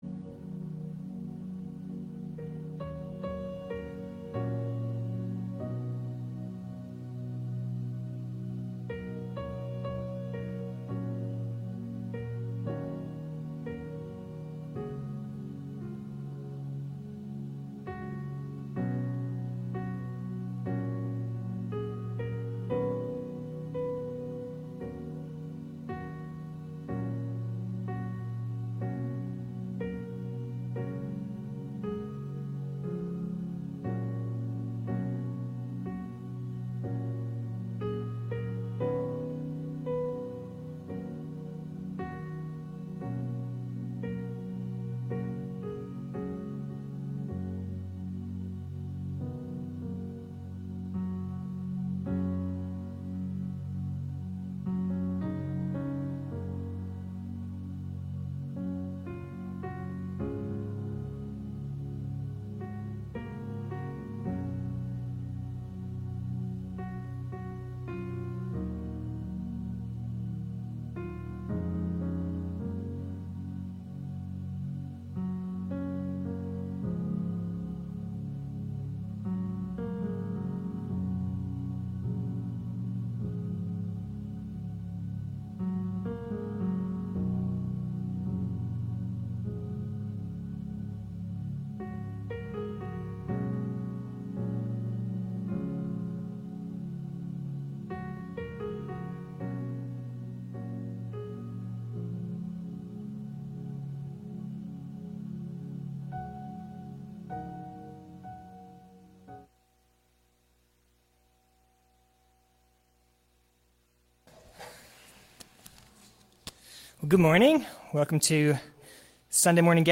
Series Biblical Justice Service Morning Worship